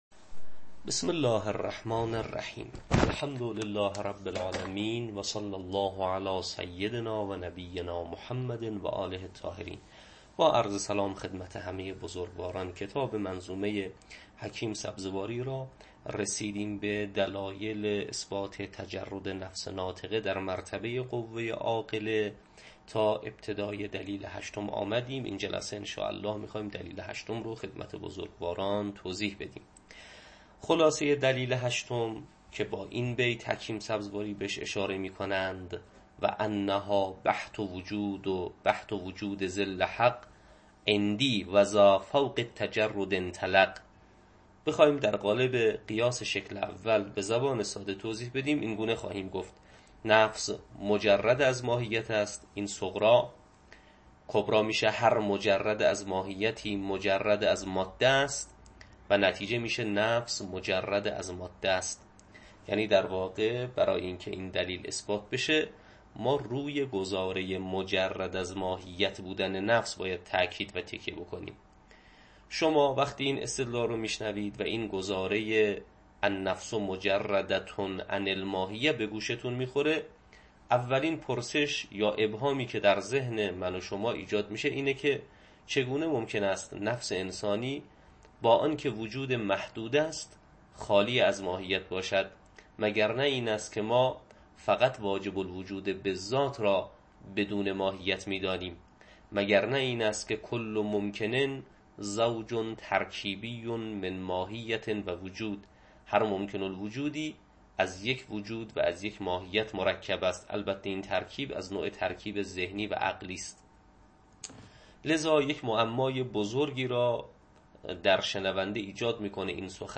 تدریس کتاب شرح منظومه